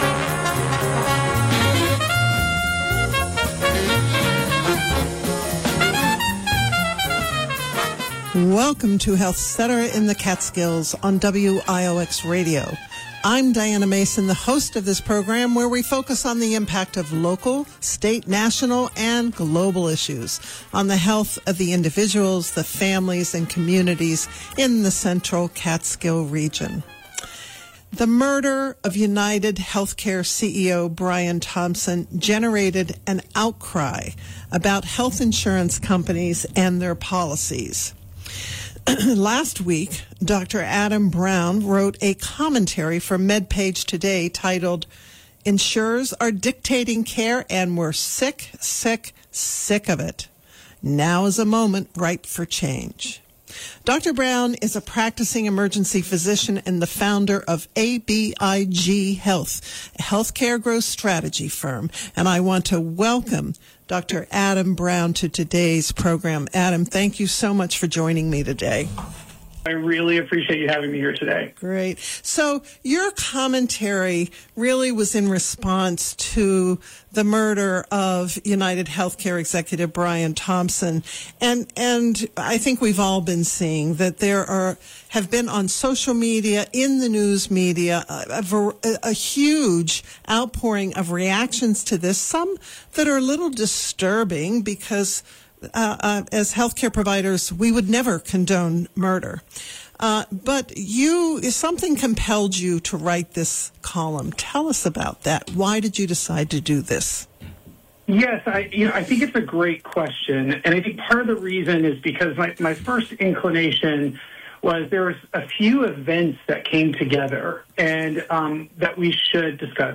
This program first aired on HealthCetera in the Catskills on WioX Radio on December 18, 2024.